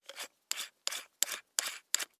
SFX_Unscrew_01.wav